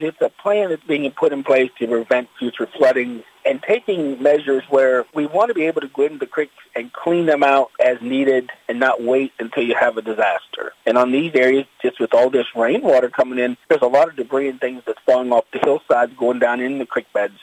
Lonaconing Mayor Jack Coburn says the work needs to be ongoing, not once and done…